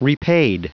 Prononciation du mot repaid en anglais (fichier audio)